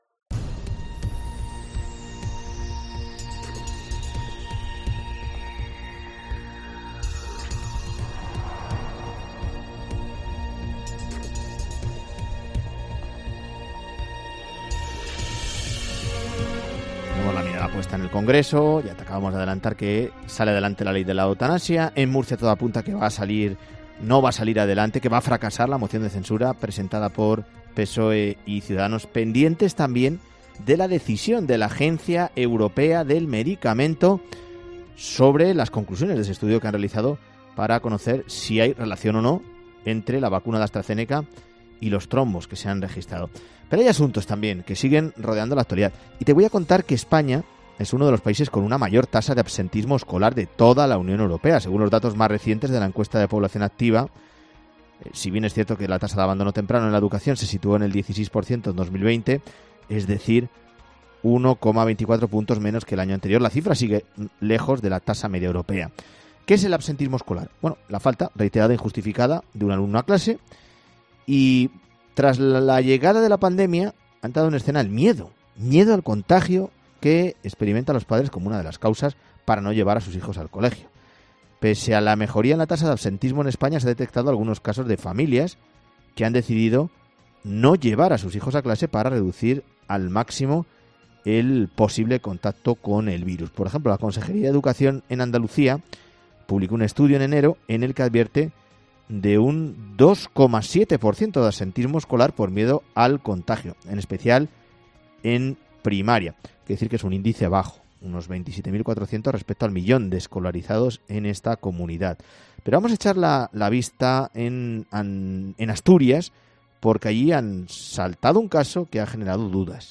Nos explica el letrado: “Mal que les pese a los padres, todo apunta a que tiene fundamento esta sentencia.